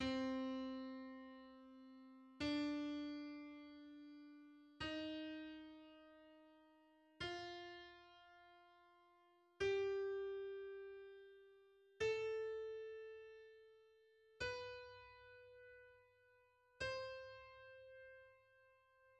c-moll
Gama c-moll w odmianie doryckiej (z VI i VII stopniem podwyższonym o półton w stosunku do gamy h-moll naturalnej):